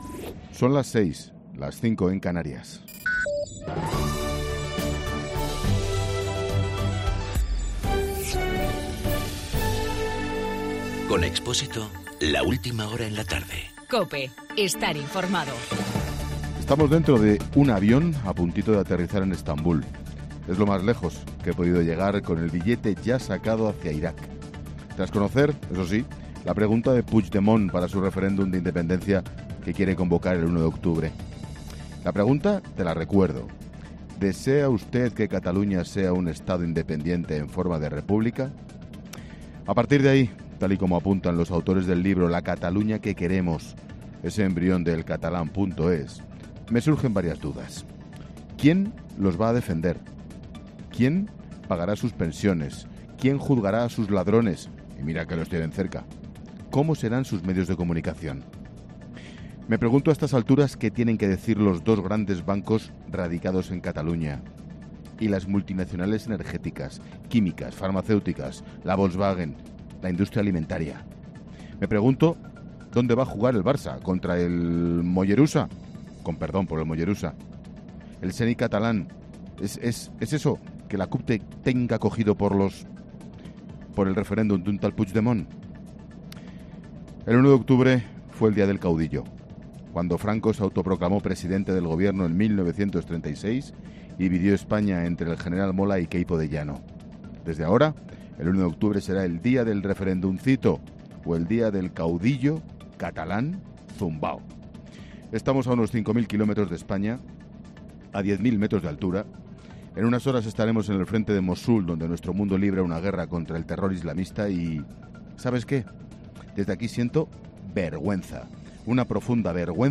Monólogo de Expósito
El monólogo de Ángel Expósito de las 18h desde Estabul, camino de Mosul. Cataluña ha anunciado el 1 de octubre como la fecha para el referéndum de independencia, día también del Caudillo.